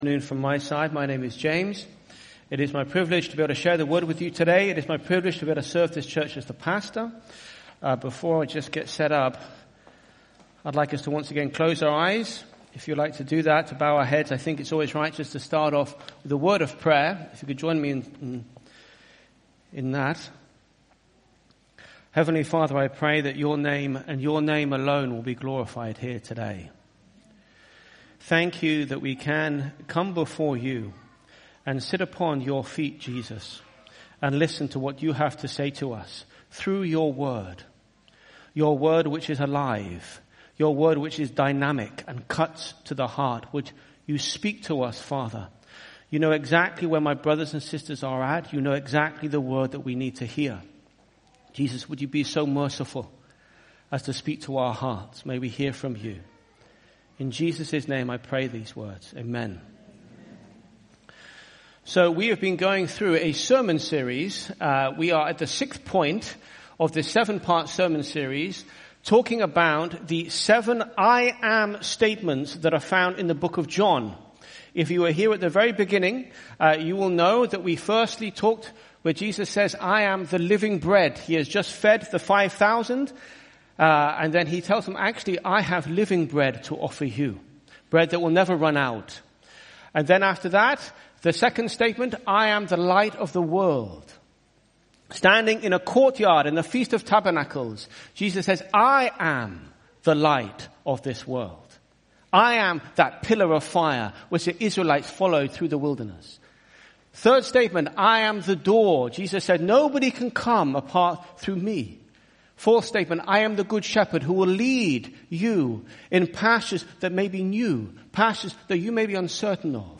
IBC Hamburg Sermon